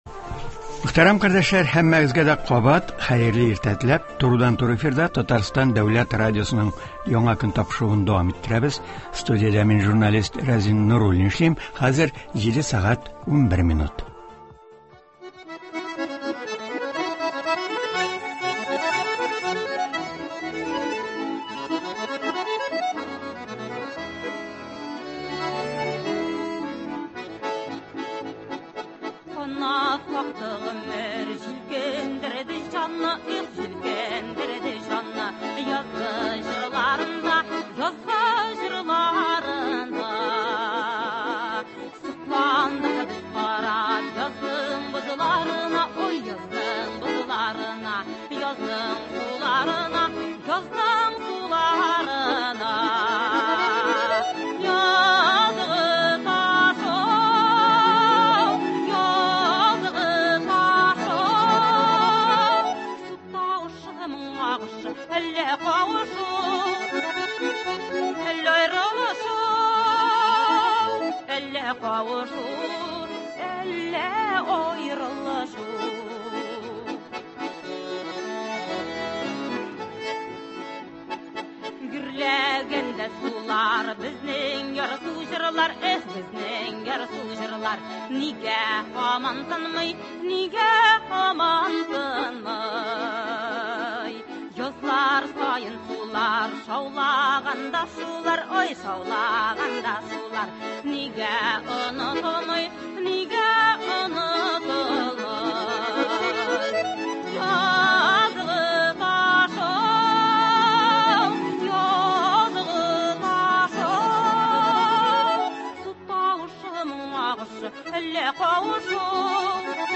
тыңлаучылар сорауларына җавап бирәчәк